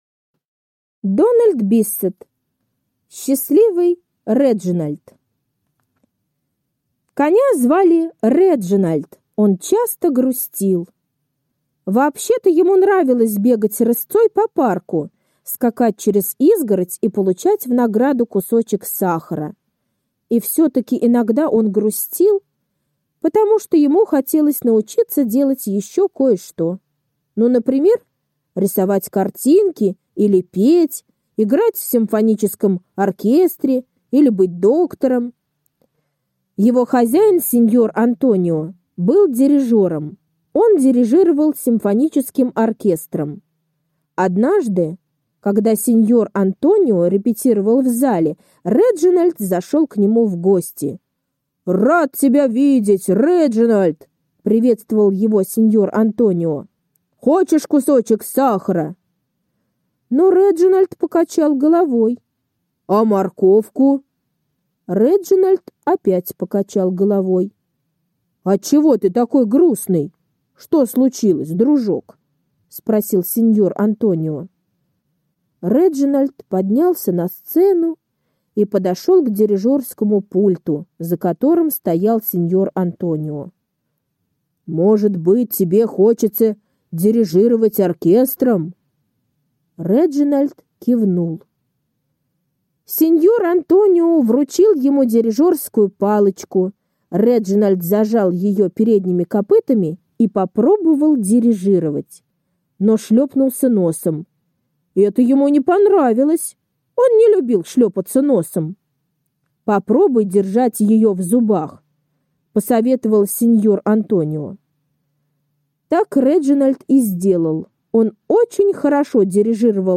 Счастливый Реджинальд — аудиосказка Дональда Биссета. Как конь Реджинальд осуществил свою мечту и сыграл в симфоническом оркестре…